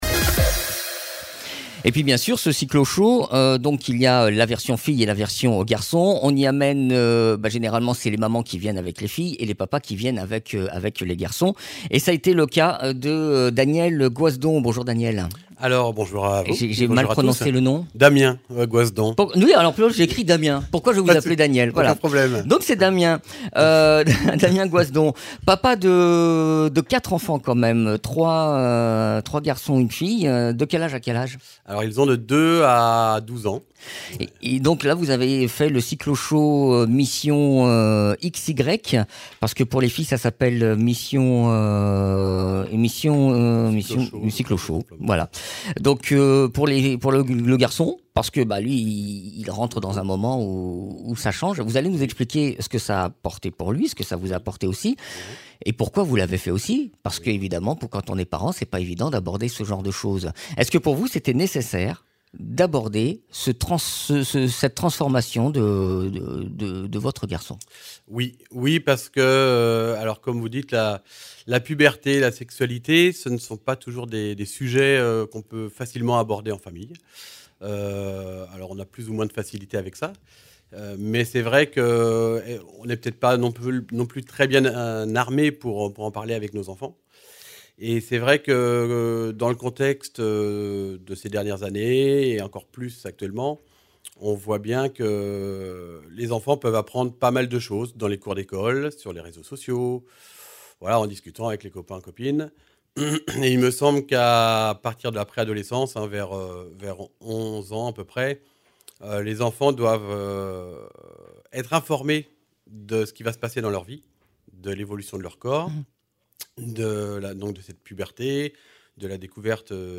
Le Cycloshow est un atelier rassemblant les parents et leurs pré-adolescents. Le but est de les préparer sur les changements qui arrivent. Témoignage d’un papa.
Interviews